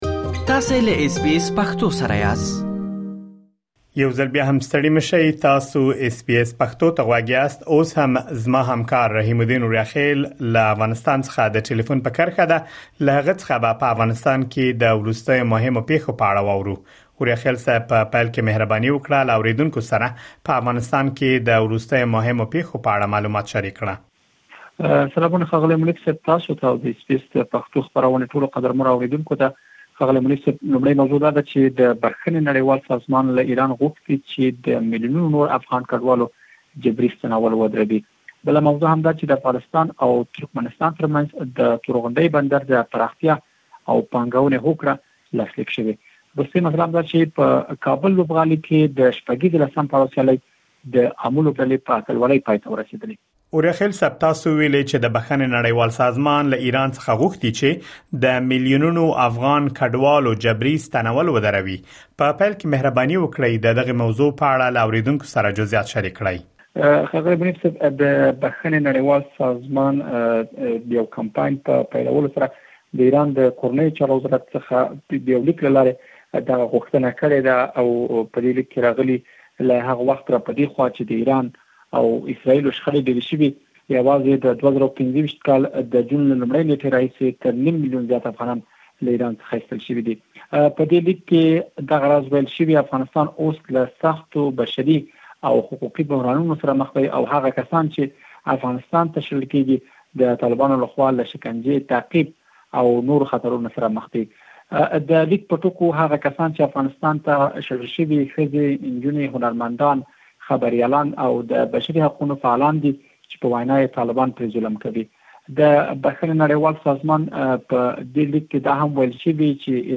مهم خبرونه: .د بښنې نړیوال سازمان له ایراني چارواکو غوښتې چې د افغان کډوالو جبري ستنول ودروي د افغانستان او ترکمنستان ترمنځ د تورغونډۍ بندر د پراختیا او پانګونې هوکړه لاسلیک شوه. کابل لوبغالی کې د شپږیږې لسم پړاو سیالۍ د امو لوبډلې په اتلولۍ پای ته ورسیدلې.